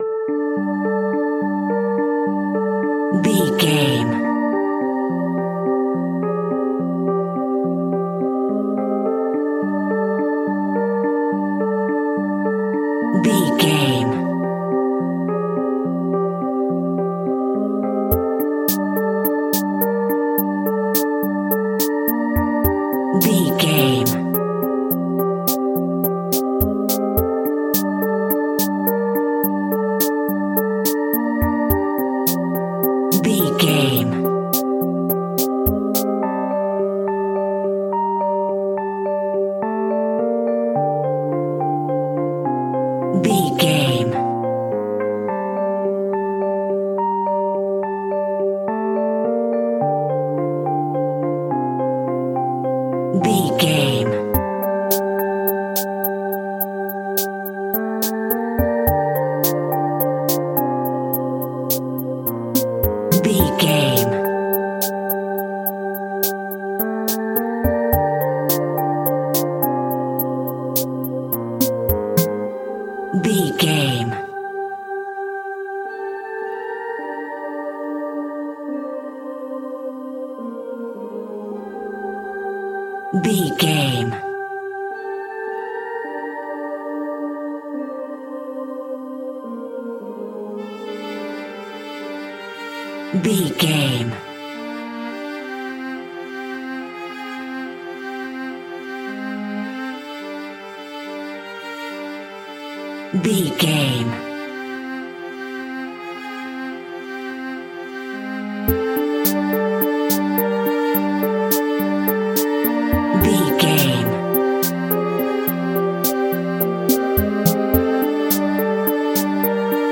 In-crescendo
Dorian
scary
ominous
dark
suspense
haunting
eerie
synthesizer
drums
keyboards
ambience
pads
eletronic